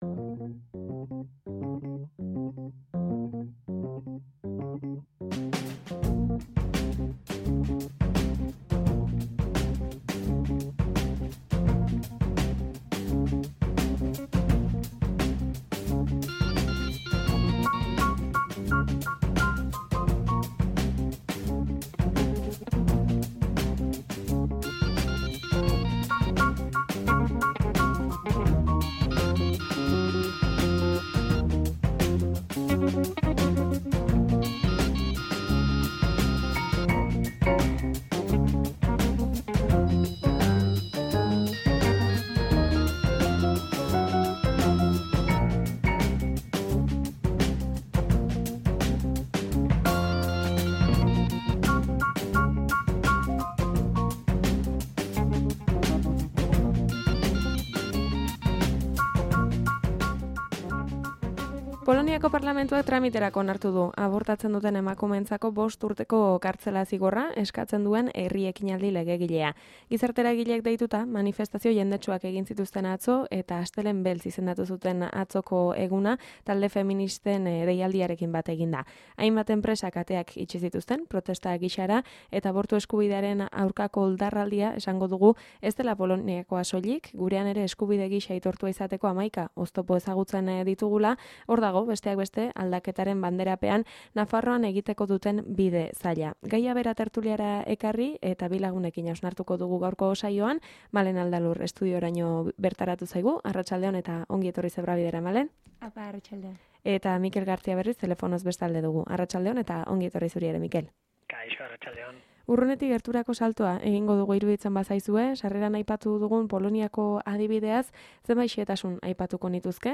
TERTULIA: kontserbadoreen eta negozio zaleen trikimailuak, abortu eskubidea ez aitortzeko